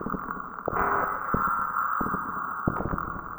Abstract Rhythm 33.wav